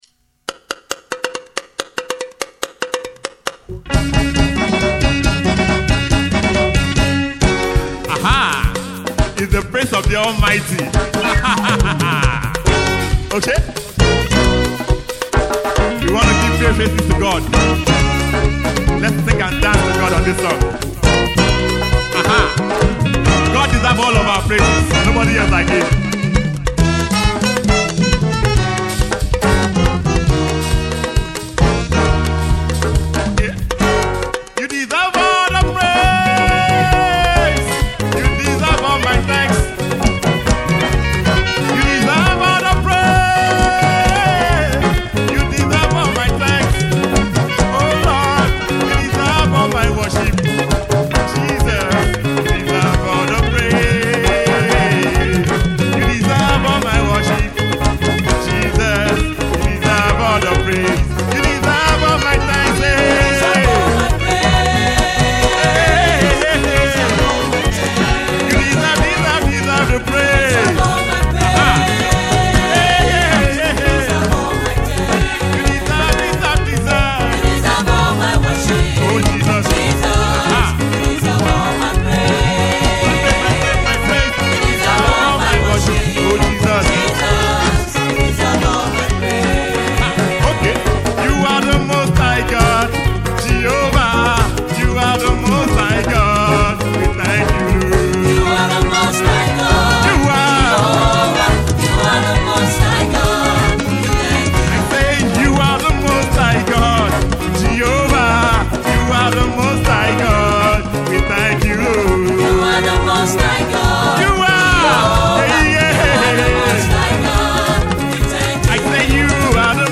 Nigerian gospel singer, songwriter